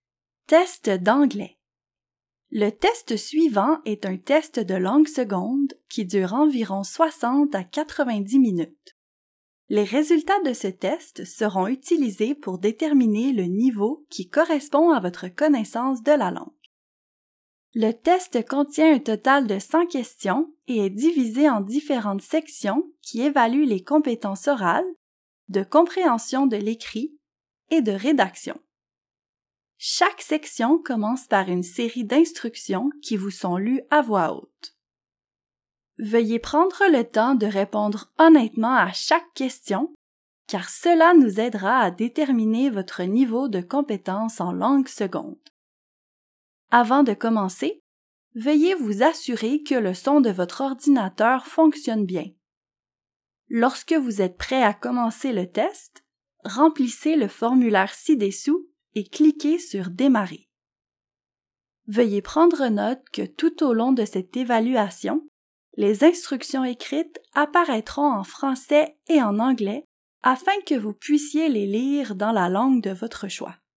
Each section begins with a series of instructions that are read aloud to you.